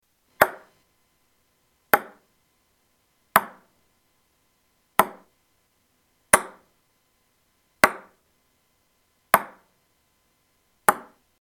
Ping Pong Ball Hits
Tags: Ping Pong Sounds Ping Pong clips Ping Pong Table tennis Table tennis clips